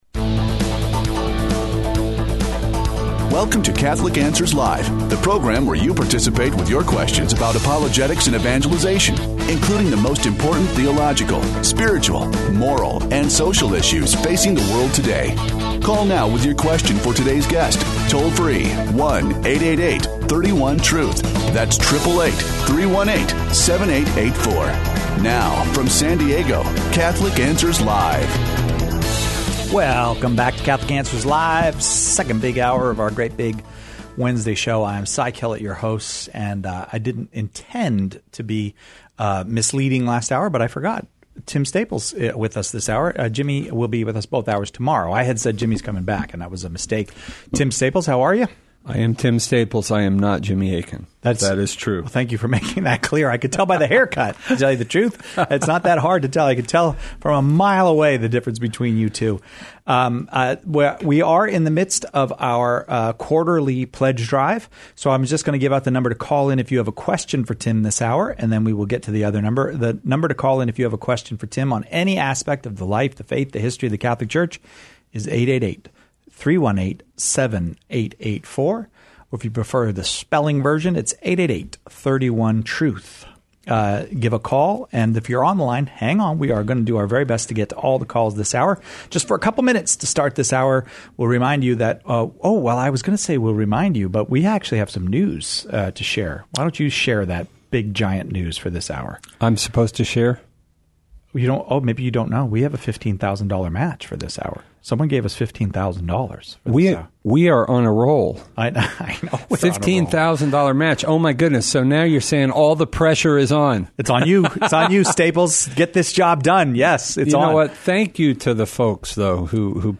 Open Forum